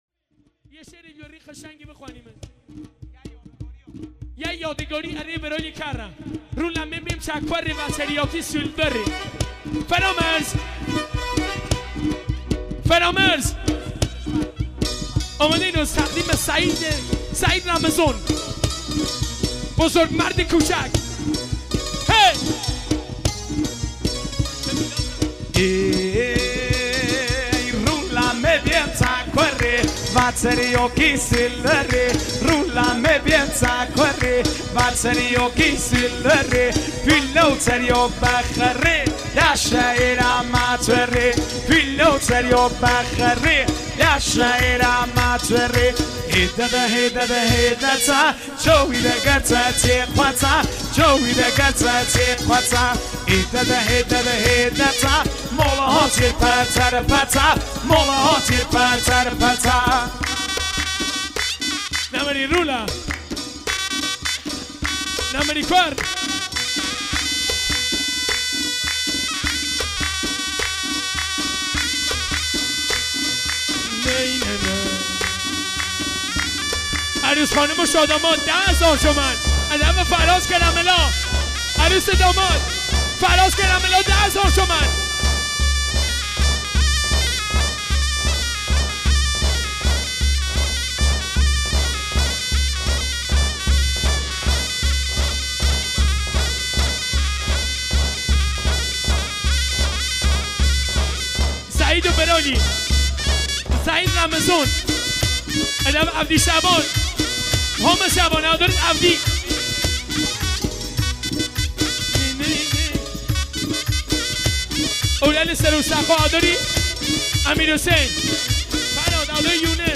اهنگ شاد لکی و لری با ارگ